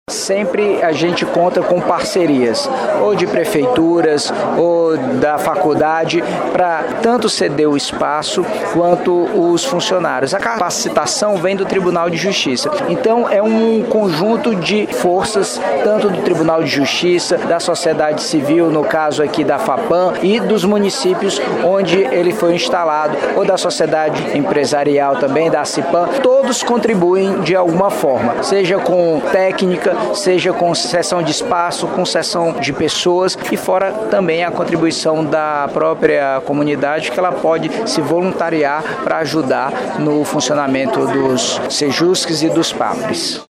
O diretor do Fórum de Pará de Minas, Juiz Antônio Fortes de Pádua Neto, também se mostrou satisfeito com a parceria firmada com a Fapam: